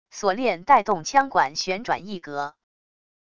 锁链带动枪管旋转一格wav音频